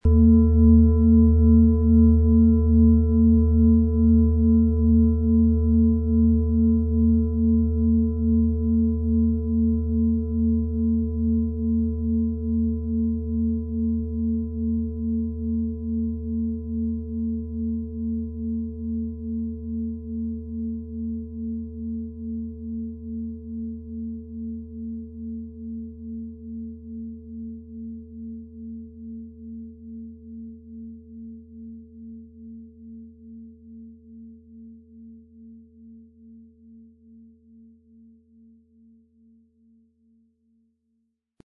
• Tiefster Ton: Eros
Unter dem Artikel-Bild finden Sie den Original-Klang dieser Schale im Audio-Player - Jetzt reinhören.
Den passenden Klöppel erhalten Sie umsonst mitgeliefert, er lässt die Schale voll und wohltuend klingen.
PlanetentöneThetawelle & Eros
MaterialBronze